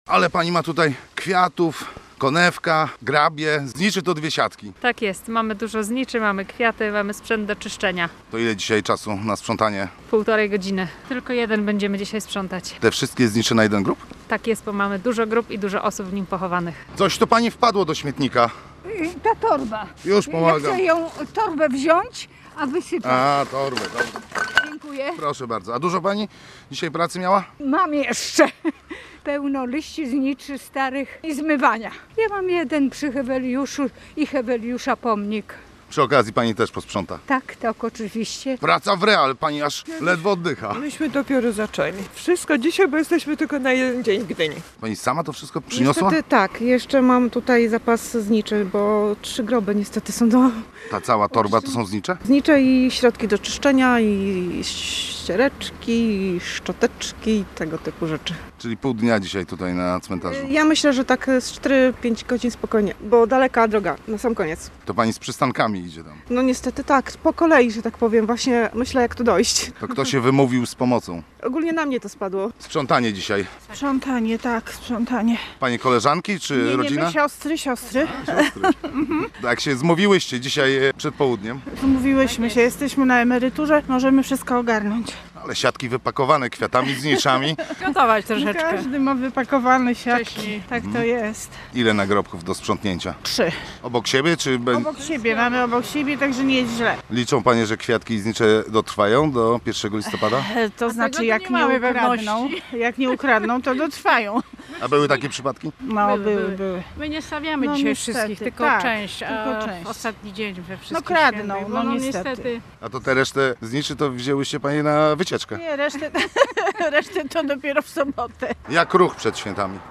Cmentarz przy ulicy Witomińskiej w Gdyni odwiedził z mikrofonem nasz reporter: